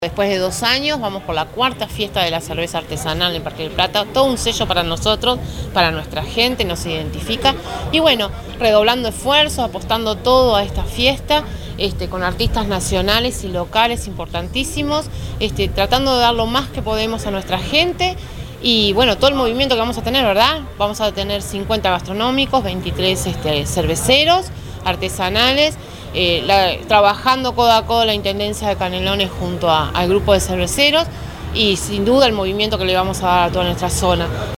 La Alcaldesa del Municipio de Parque del Plata, Tania Vecchio, habló de la identidad que le genera esta fiesta a la comunidad y afirmó que “es un sello para nosotros, para nuestra gente, nos identifica”.
tania_vechio_-_alcaldesa_de_parque_del_plata.mp3